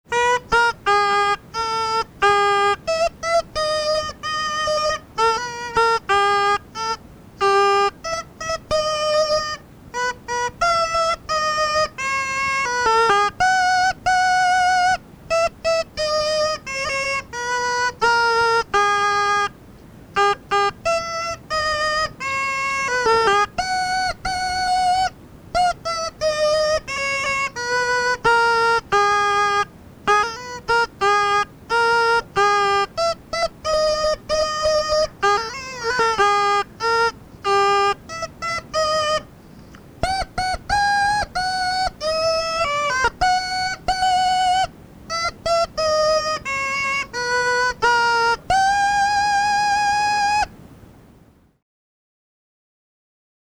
Голоса уходящего века (Курское село Илёк) Роспрягайте, хлопцы, коней (рожок, инструментальная версия)